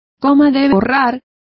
Complete with pronunciation of the translation of eraser.